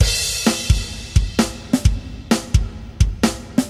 碰撞摇滚鼓REX
Tag: 130 bpm Electronic Loops Drum Loops 636.09 KB wav Key : Unknown